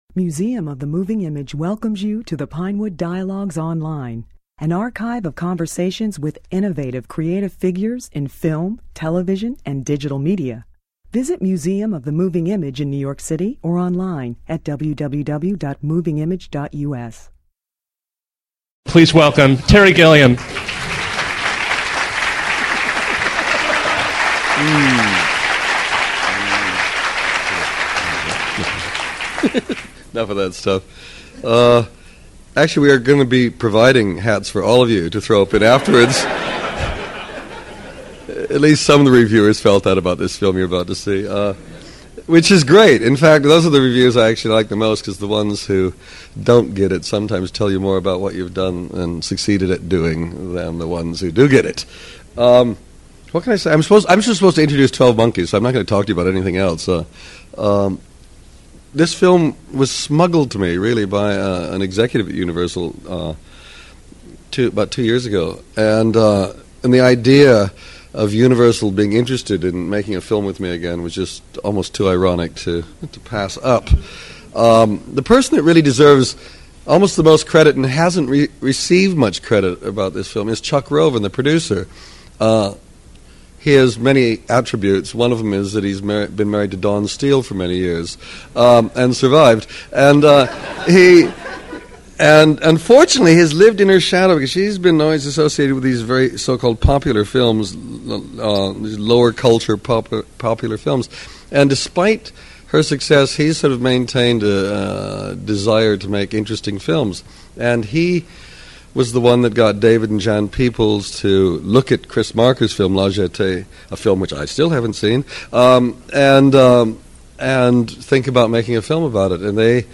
Ironically, the same studio later financed and released Gilliam's 12 Monkeys , which was the number-one film in the country when Gilliam spoke at the Museum. He greeted full-house audiences twice that weekend—with 12 Monkeys and Brazil —the latter on the day of the blizzard of '96.